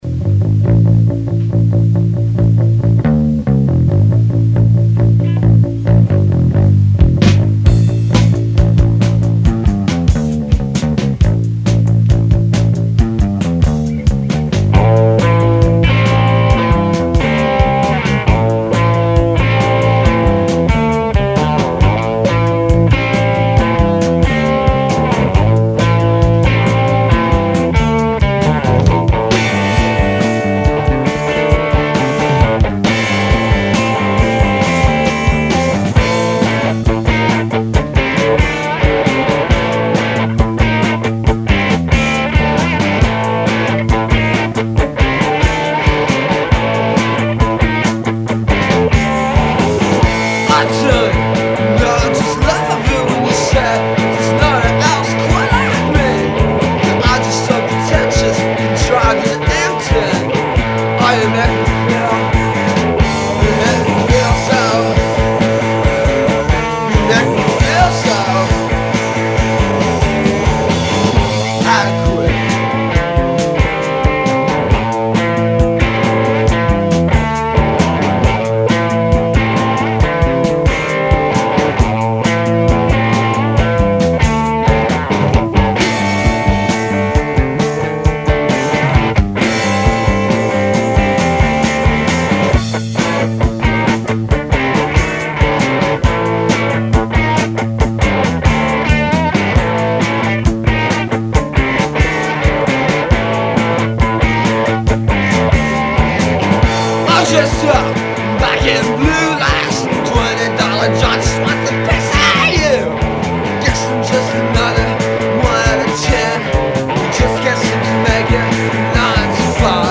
guitar/vocals
drums
bass